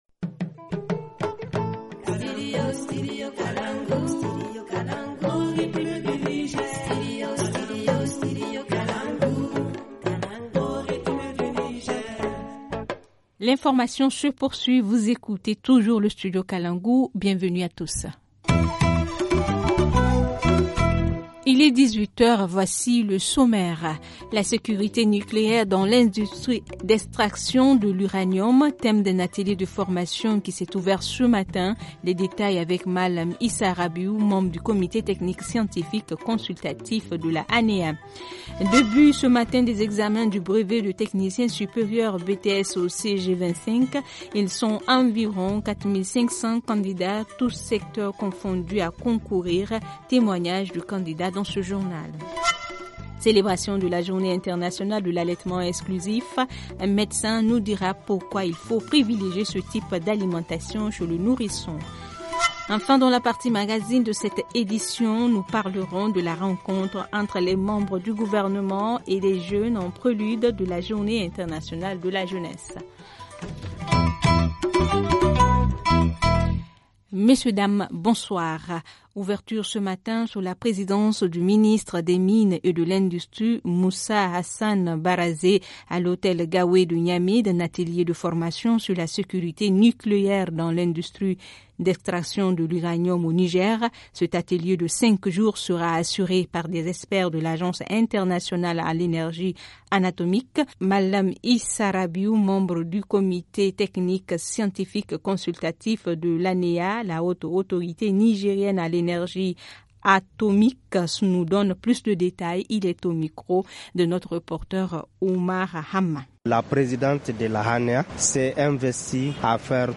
Ils sont environs 4500 candidats tous secteurs confondus à concourir. Témoignages de candidats dans ce journal.